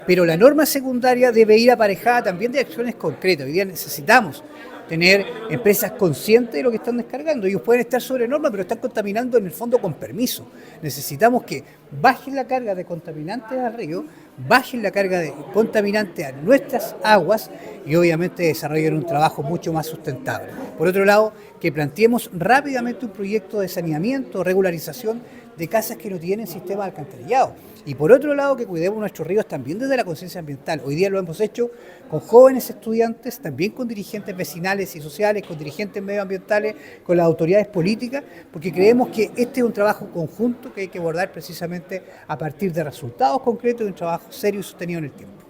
Entre las acciones que se debe tomar por parte de las autoridades se encuentra avanzar en materias de saneamiento para viviendas que no cuentan con alcantarillado; normar el funcionamiento de las industrias que colindan a los cauces del río y además fomentar la educación ambiental, según detalló Francisco Reyes.